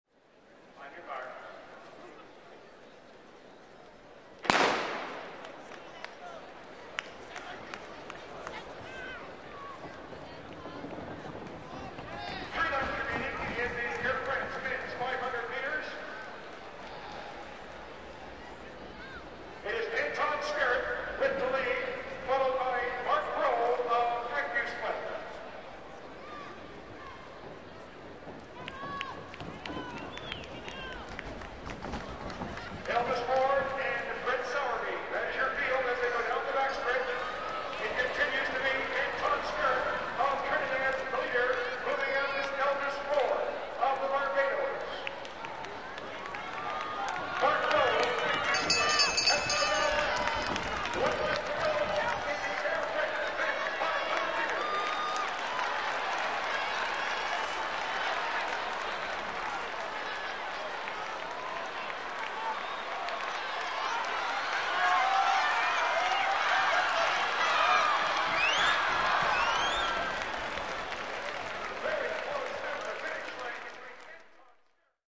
Звуки спорта
Громкий звук финиша на 400 метрах, ликующие крики трибун